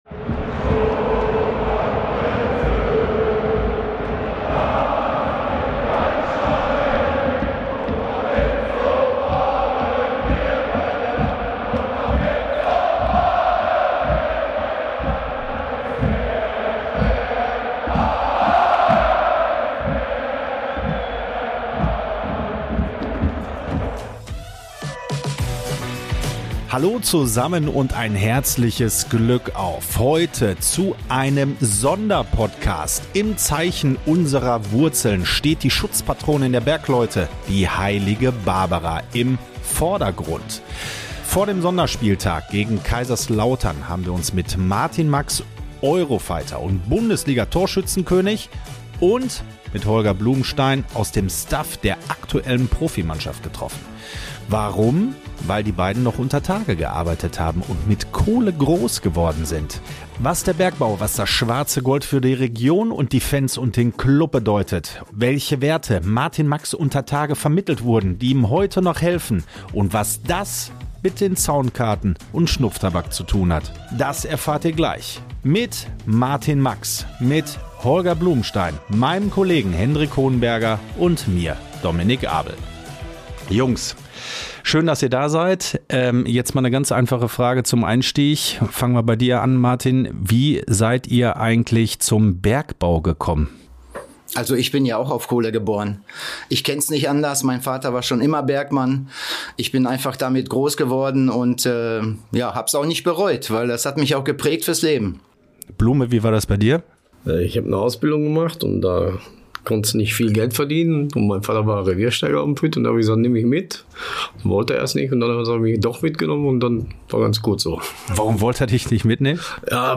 Sie sprechen mit den beiden Moderatoren